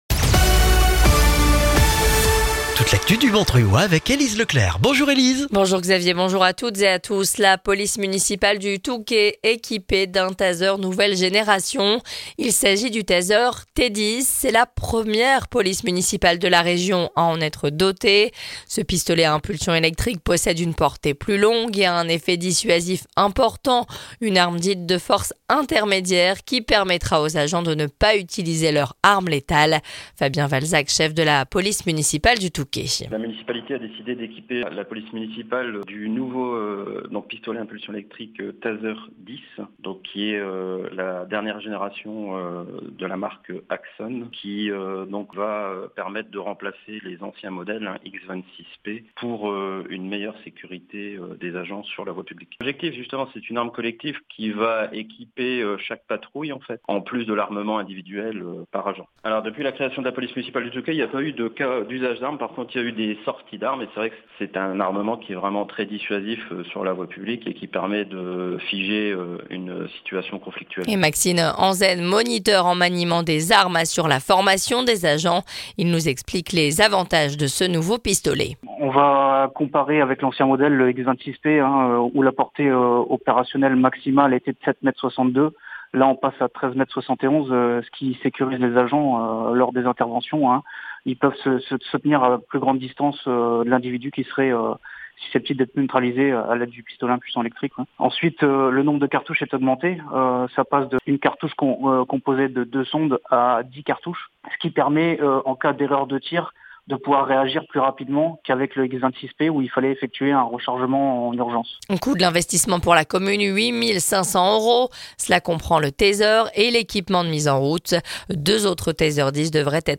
Le journal du mercredi 2 octobre dans le Montreuillois